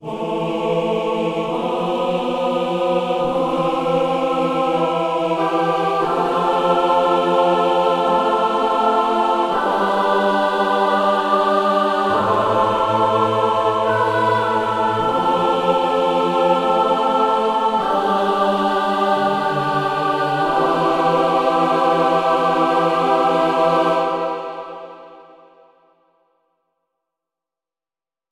Here's 4 free program sounds powered by choir samples.
free-choir2.mp3